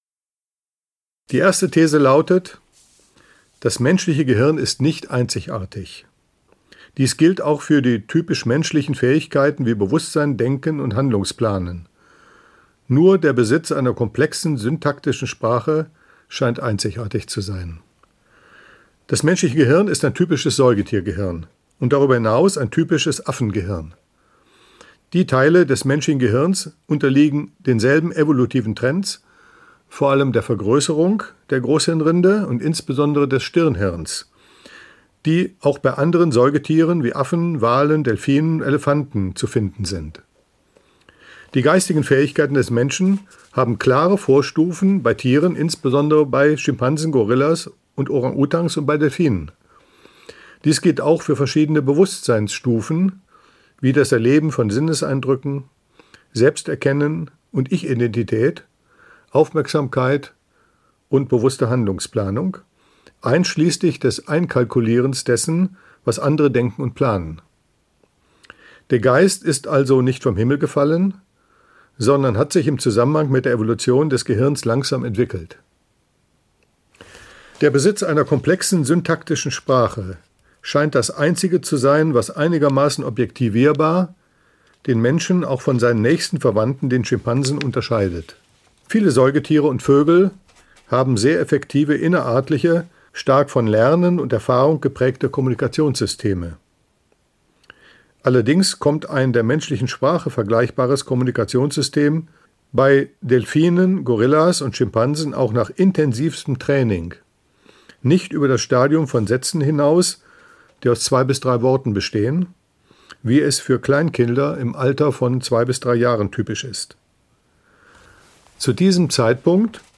Wie wir Entscheidungen treffen - Hirnforschung und Willensfreiheit - Gerhard Roth - Hörbuch